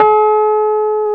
Index of /90_sSampleCDs/Roland L-CD701/KEY_E.Pianos/KEY_Rhodes
KEY RHODS 09.wav